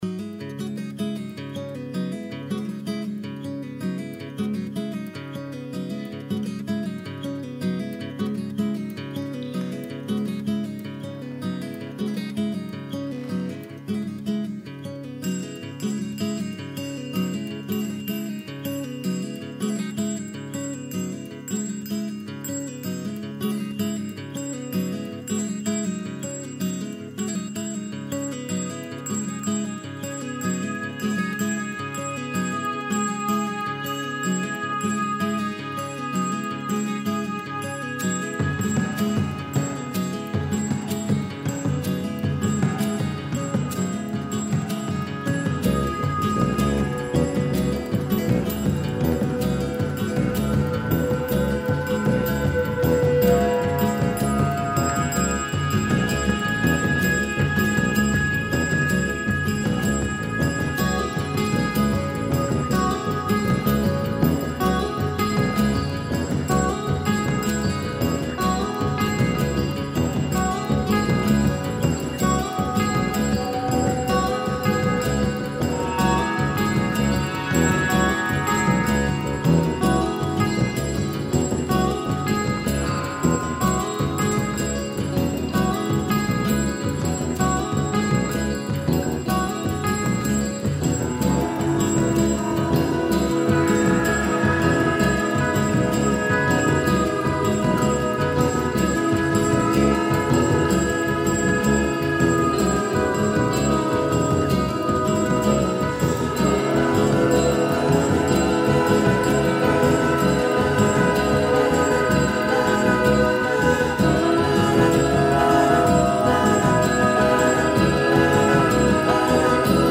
"Scene from a Church" - atmospheric acoustic tune in 5/8